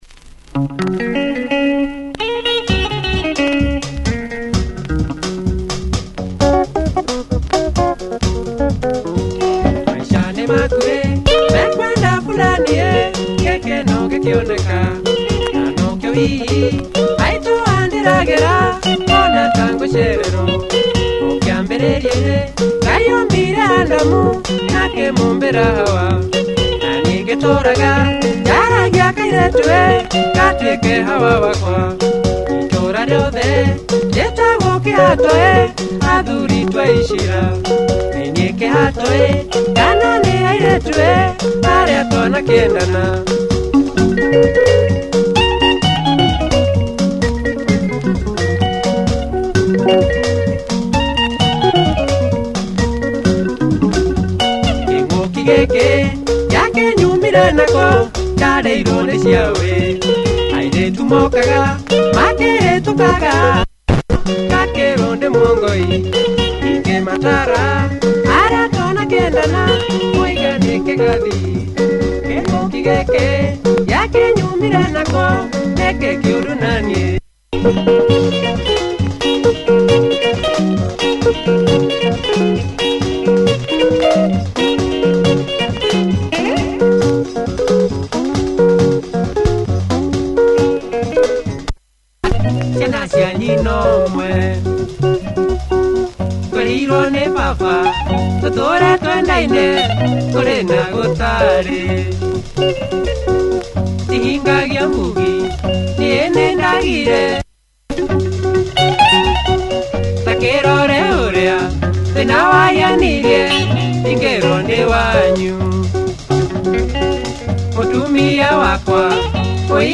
Uplifting kikuyu benga